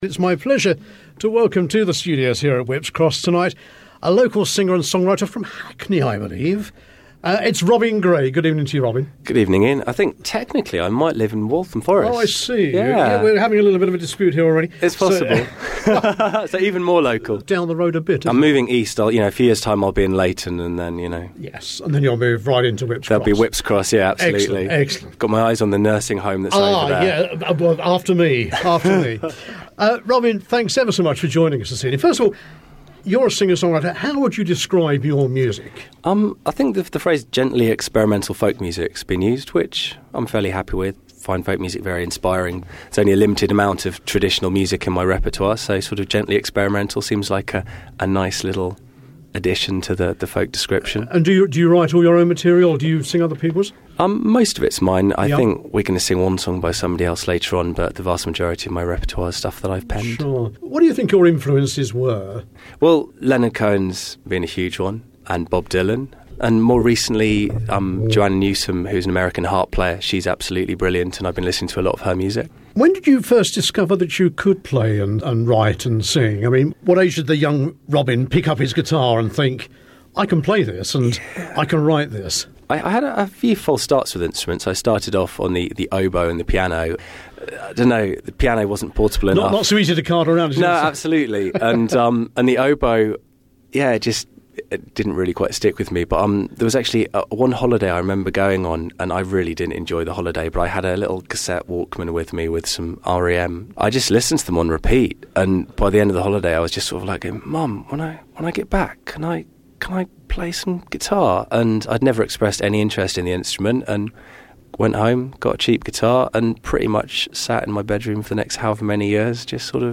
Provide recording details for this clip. visited our studios just before Christmas 2012 to chat and play a few of his tracks live